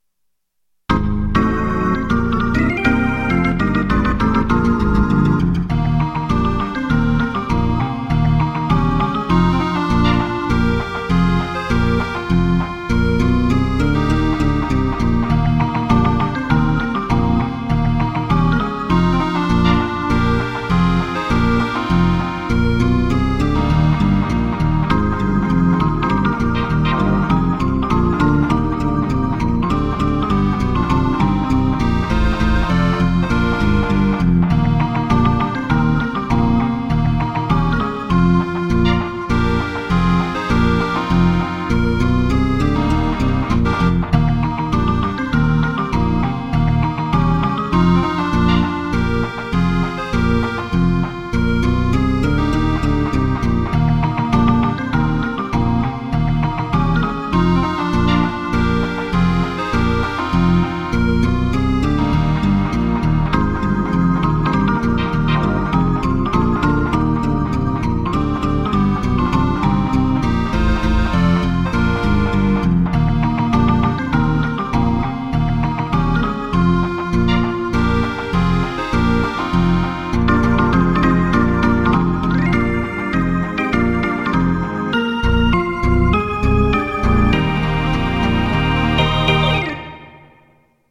для аккордеона и баяна
Фокстрот